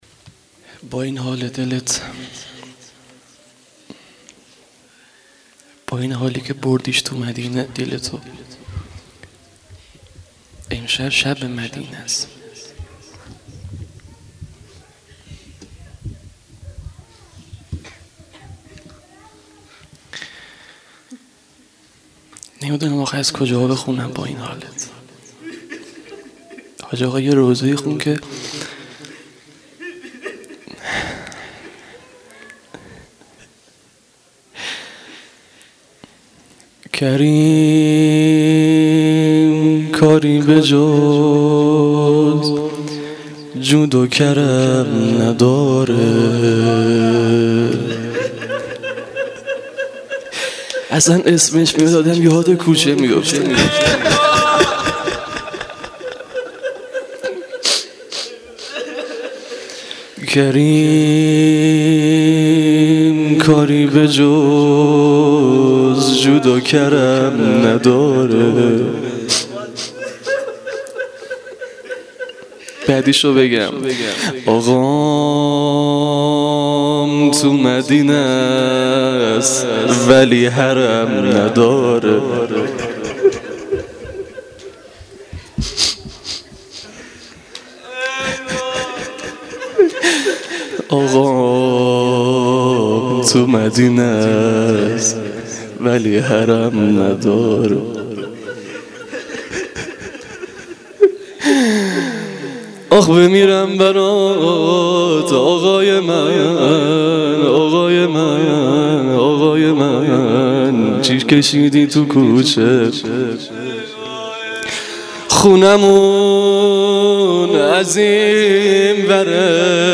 روضه شب ششم محرم
Rozeh-Shabe-06-moharram94.mp3